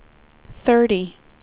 WindowsXP / enduser / speech / tts / prompts / voices / sw / pcm8k / number_50.wav